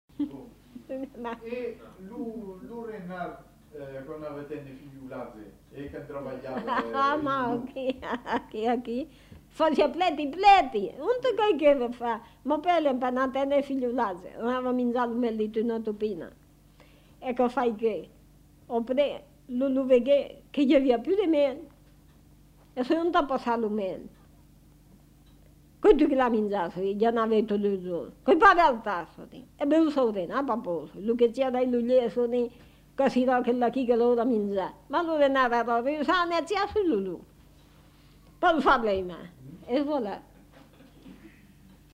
Aire culturelle : Périgord
Lieu : La Chapelle-Aubareil
Genre : conte-légende-récit
Effectif : 1
Type de voix : voix de femme
Production du son : parlé